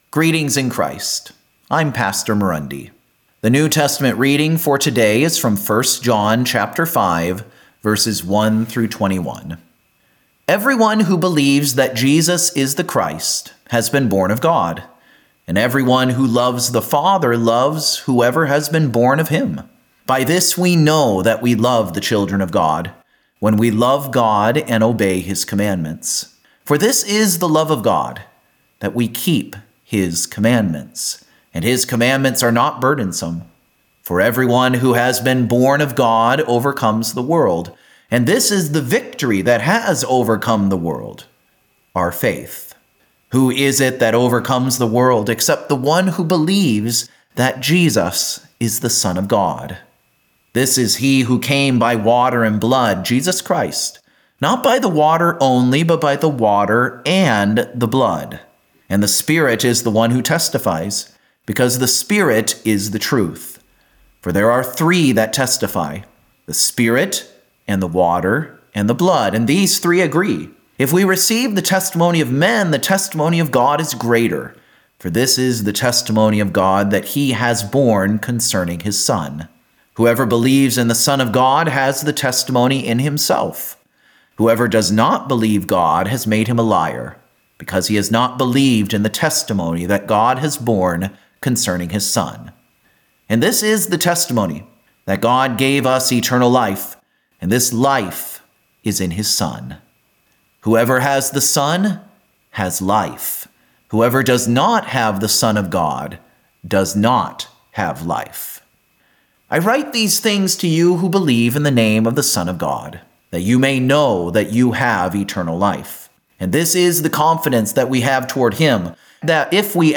This is a rebroadcast from September 16, 2016.